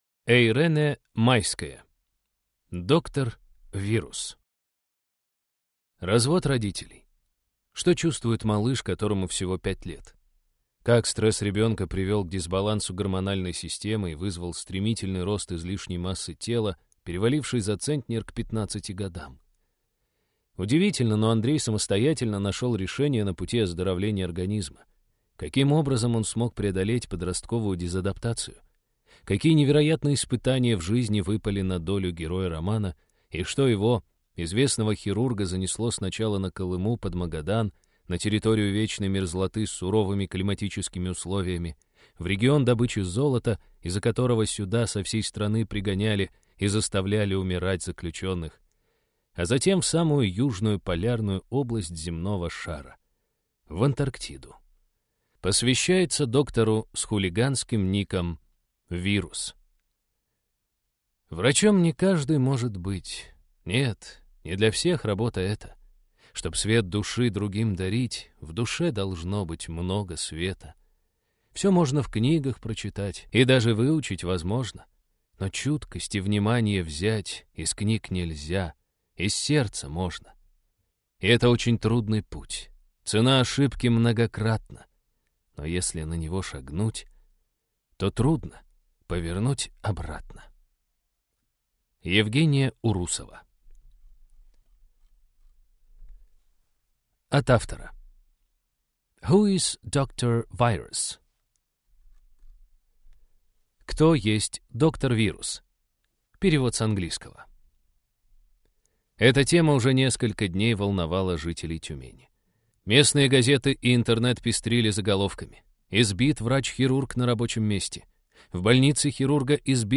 Аудиокнига Доктор VIRUS | Библиотека аудиокниг